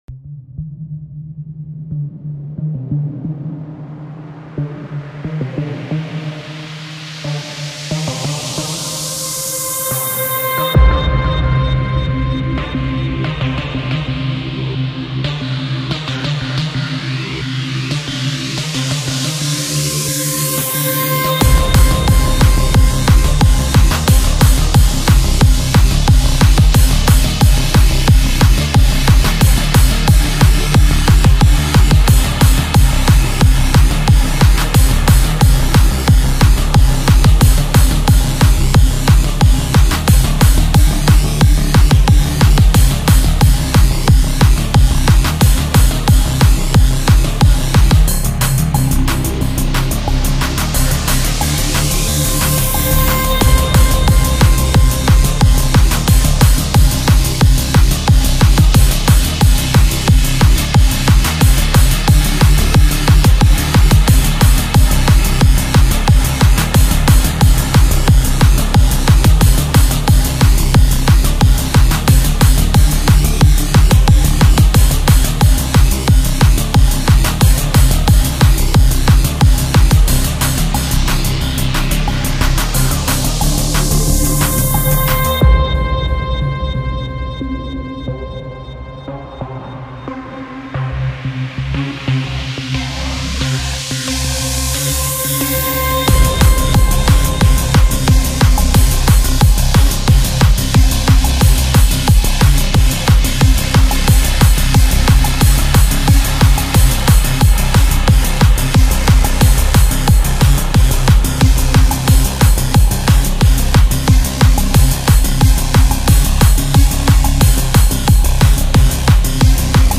Альбом: Dark Techno